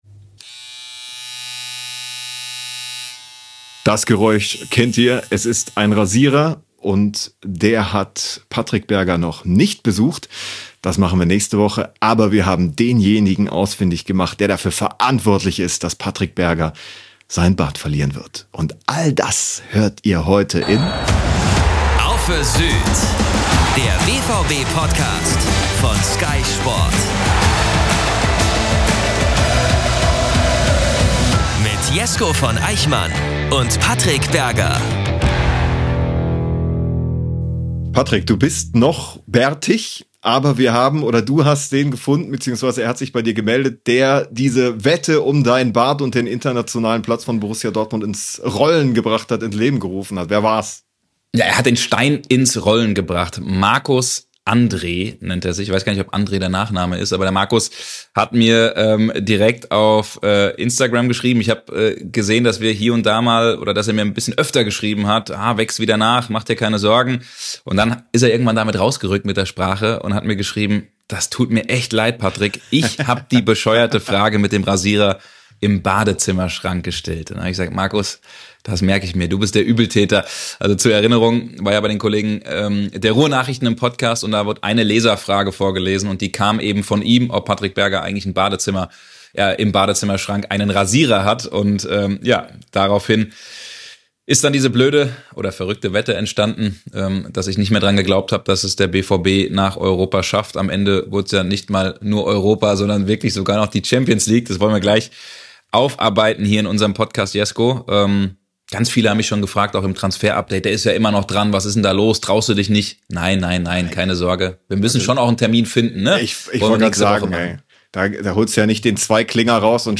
Darüber sprechen die beiden Sky-Reporter ausführlich. Es geht aber inhaltlich auch um den bevorstehenden Transfersommer, den Fahrplan bis zur Saisoneröffnung am 10. August und das bevorstehende Nations-League-Turnier: Welche BVB-Stars haben gute Chancen auf eine Nominierung?